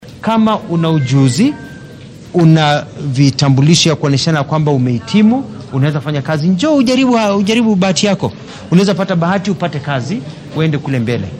Wasiir Alfred Mutua oo baaq u diraya dhallinyarada shaqo la’aanta ah ayaa yiri.